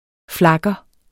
Udtale [ ˈflɑgʌ ]